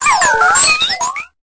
Cri de Spododo dans Pokémon Épée et Bouclier.